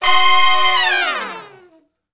014 Long Falloff (Ab) har.wav